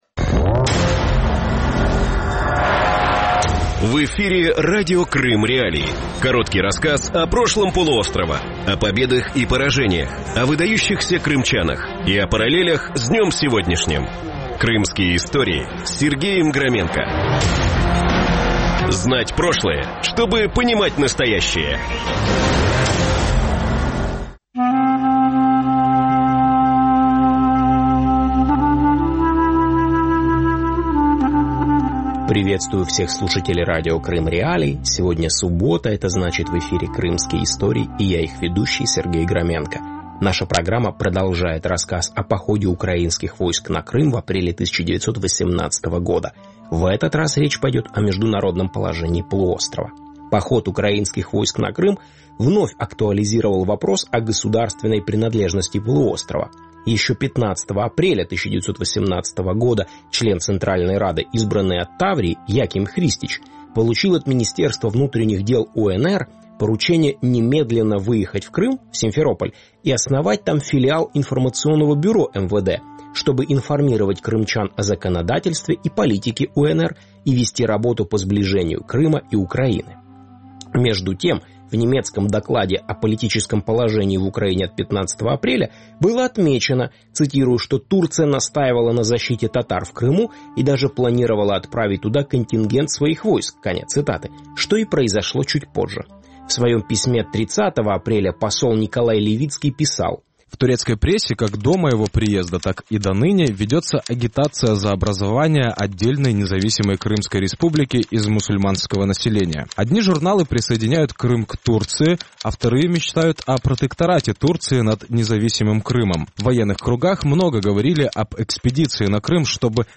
Эфир можно слушать на средних волнах AM (частота 549 килогерц), в FM-диапазоне (частота 100.7 мегагерц), а также на сайте Крым.Реалии